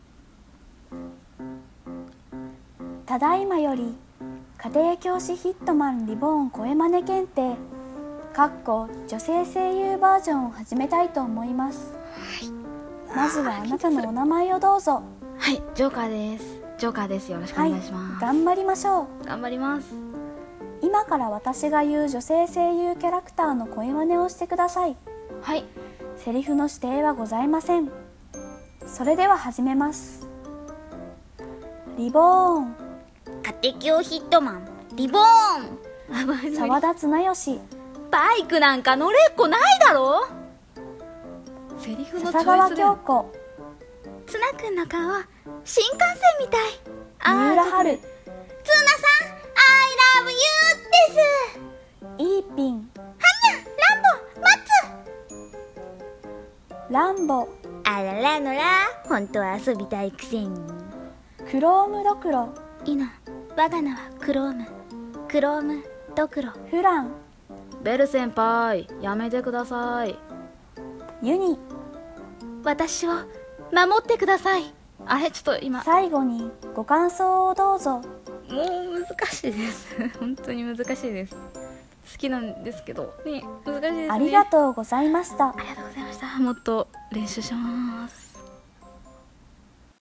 家庭教師ヒットマンリボーン声真似検定（女性声優bar）